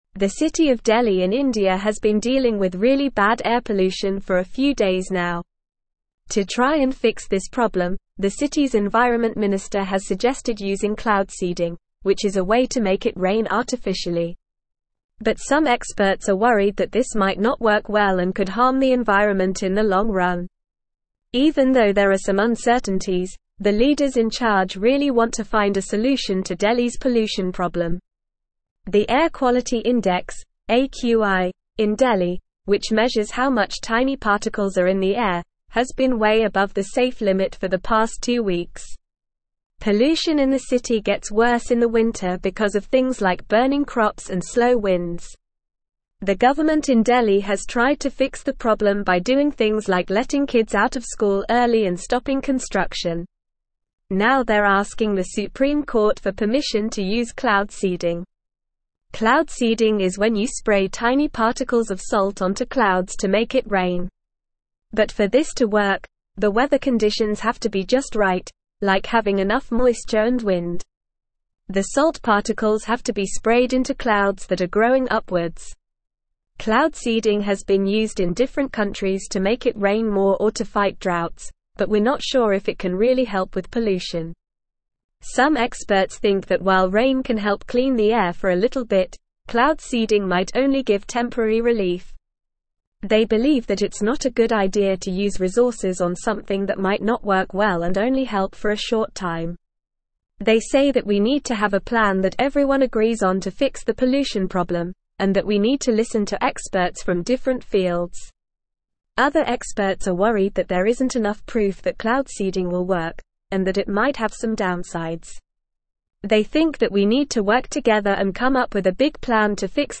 Normal
English-Newsroom-Upper-Intermediate-NORMAL-Reading-Delhi-Considers-Cloud-Seeding-to-Combat-Air-Pollution.mp3